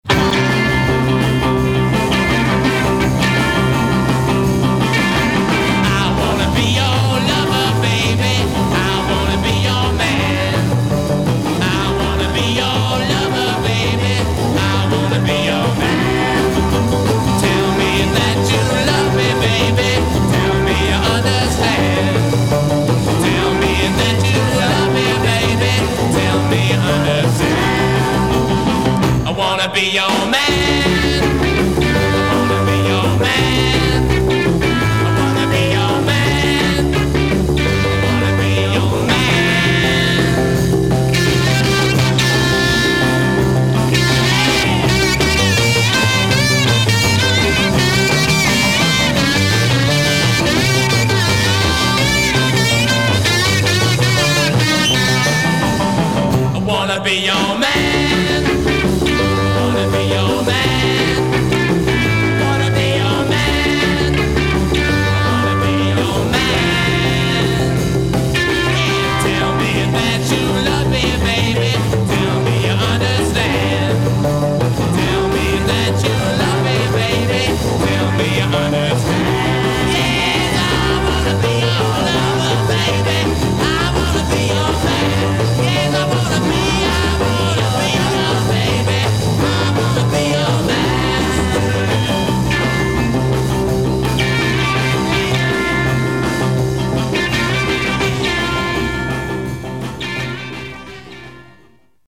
intro 0:00 4 slide guitar over guitar, bass, & drums
slide guitar version of verse melody
coda   8+ repeat intro material and fade